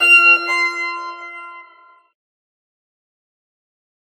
シンプルで使いやすい通知音です。